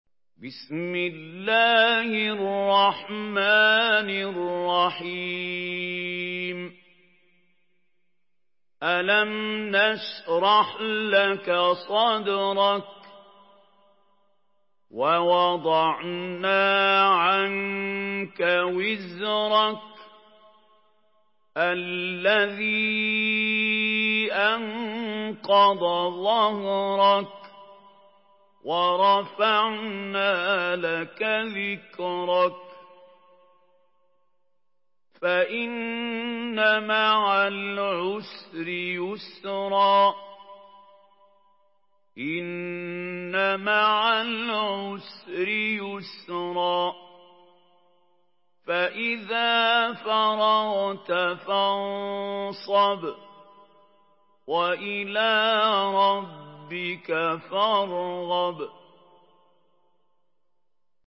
سورة الشرح MP3 بصوت محمود خليل الحصري برواية حفص
مرتل